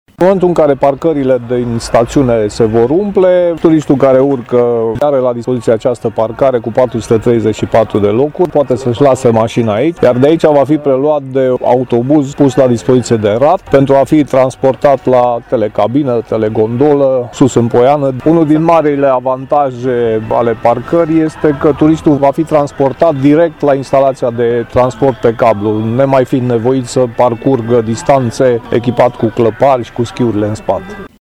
Avantajul parcării și înființării acestei linii de autobuze ne-a fost explicat de viceprimarul Brașovului, Laszlo Barabas: